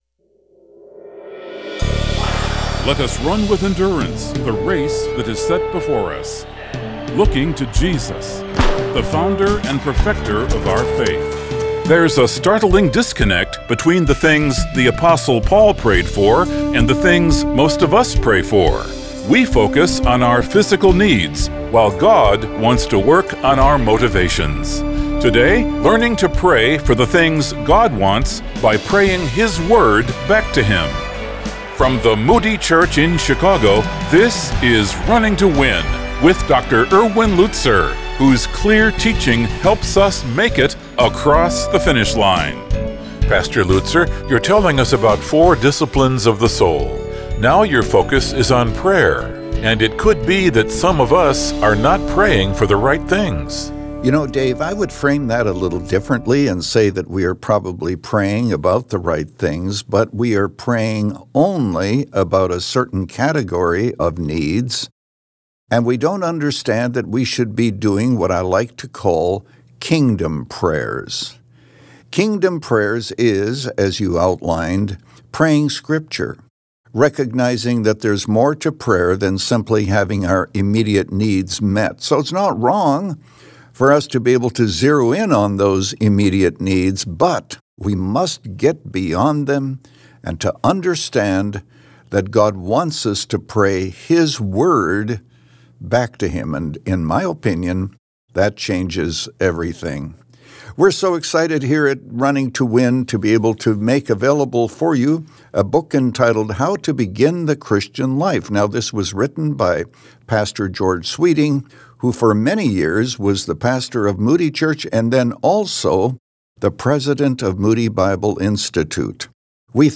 The Discipline Of Prayer – Part 2 of 2 | Radio Programs | Running to Win - 25 Minutes | Moody Church Media
Since 2011, this 25-minute program has provided a Godward focus and features listeners’ questions.